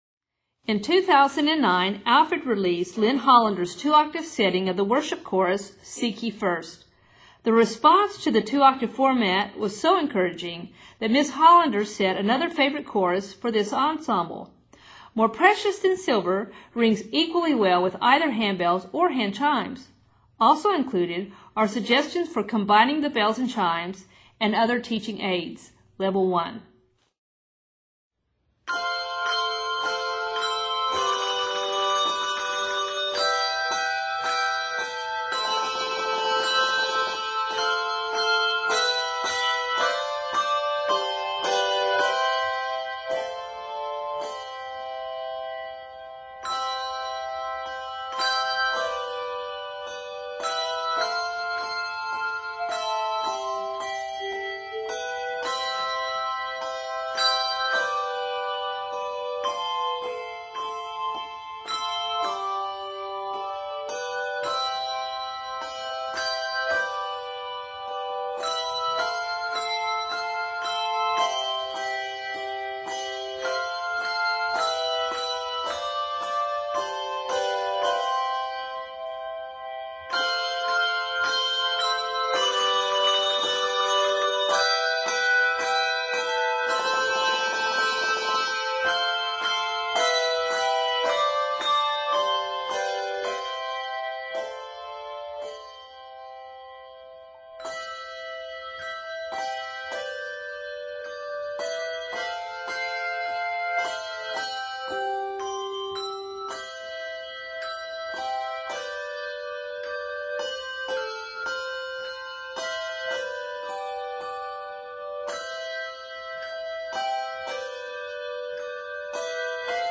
Arranged in C Major, this piece is 55 measures.